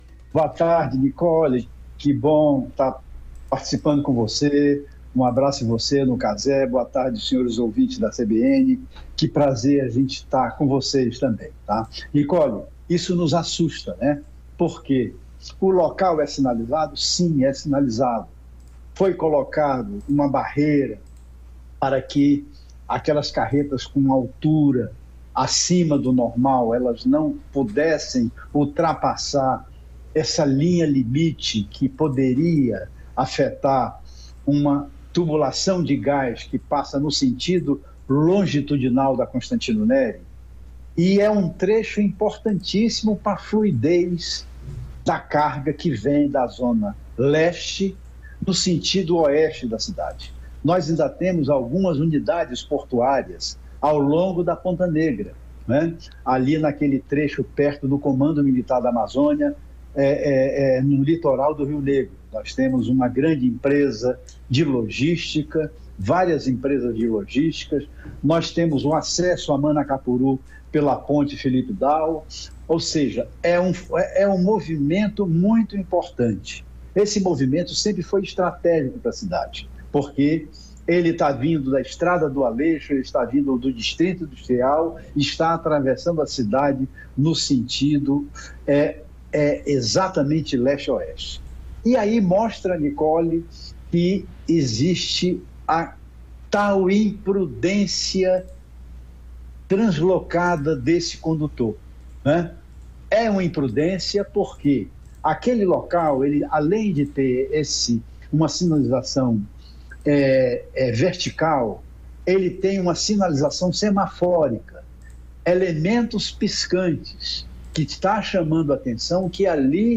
Recebemos o colunista e especialista em mobilidade urbana que comentou sobre o acidente e os problemas de logística presente na cidade.